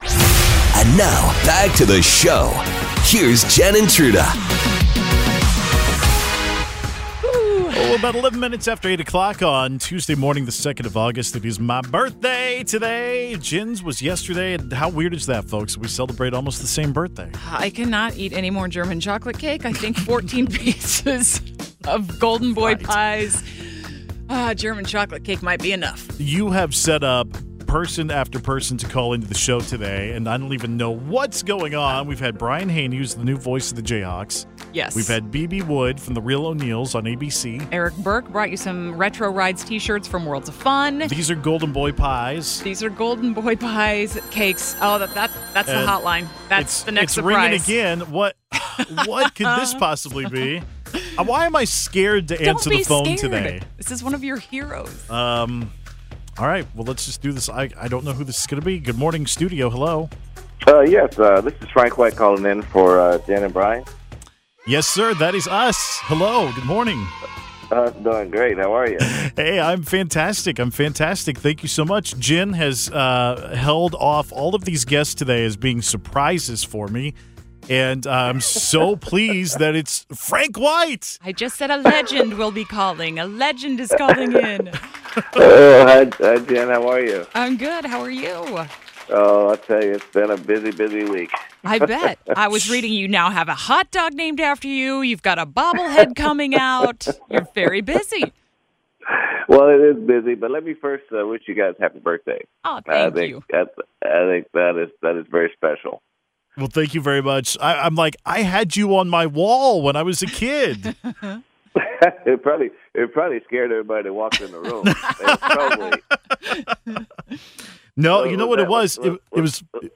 The birthday surprises continued with a call-in from Kansas City Royals legendary second baseman Frank White! Listen as the All-Star reveals plans for his new Hy-Vee bobblehead, the "Royal Frank" hot dog and his political future.